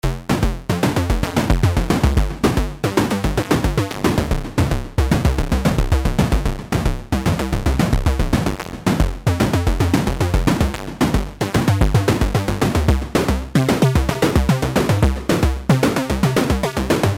Energetic Glitchy ChipTune Beat Loop – Retro 8-Bit Music
Genres: Synth Loops
Tempo: 112 bpm